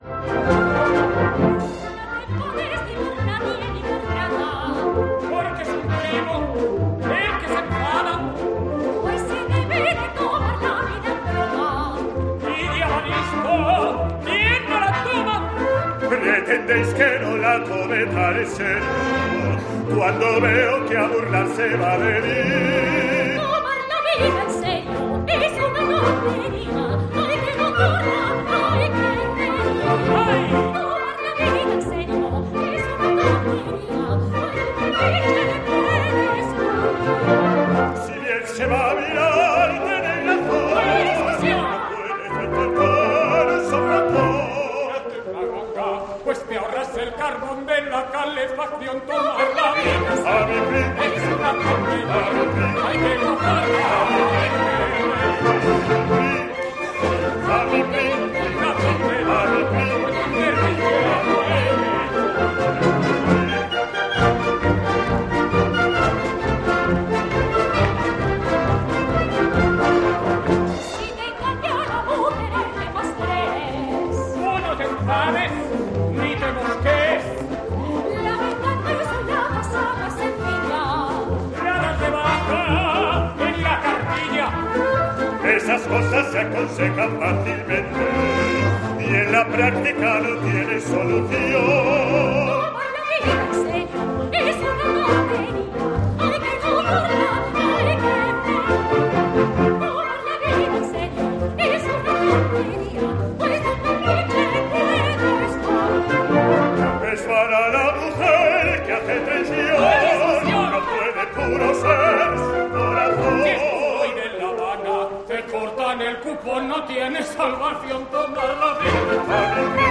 Emilio Sagi, ex director del teatro Arriaga describe la opereta "Luna de miel en el Cairo"